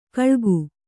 ♪ kaḷgu